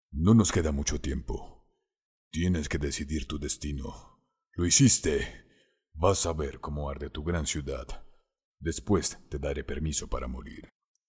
Ingeniero de profesion, capacitado en doblaje y locucion, participacion en fandubs, voz grave natural, con matices e interpretacion para alcanzar varios tonos.
kolumbianisch
Sprechprobe: Sonstiges (Muttersprache):